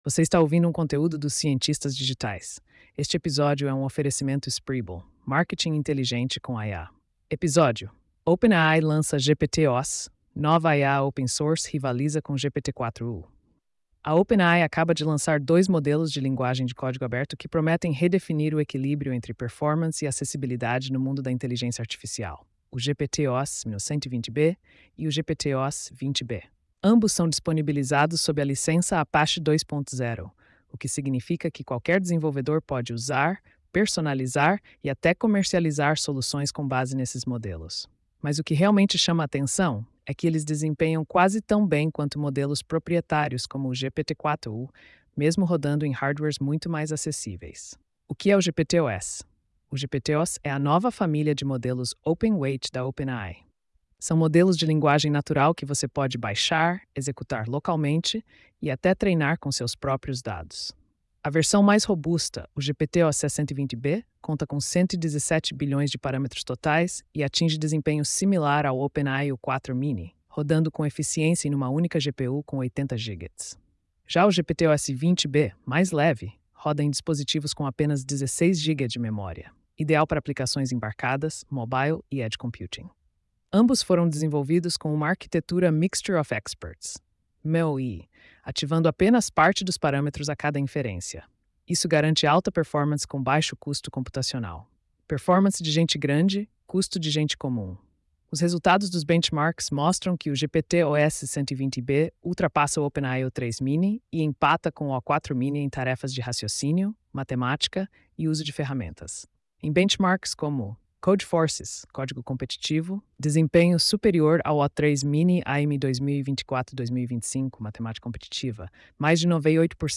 post-4171-tts.mp3